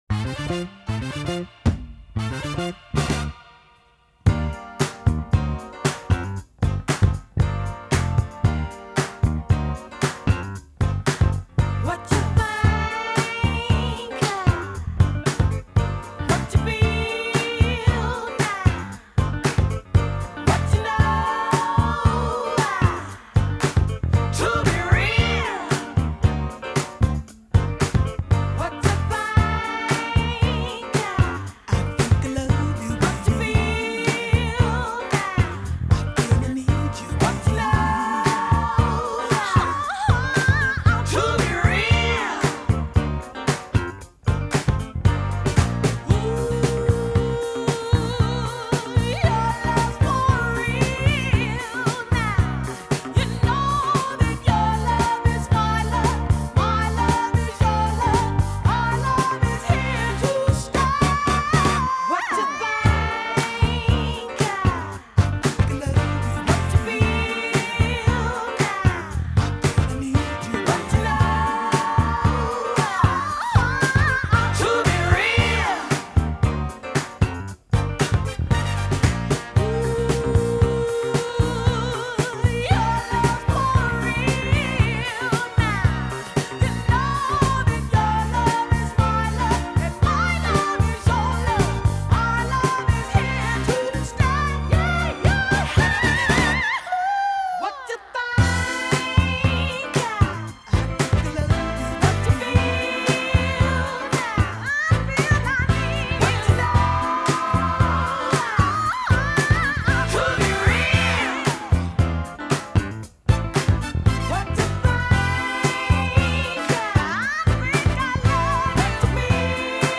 1978   Genre: Disco   Artist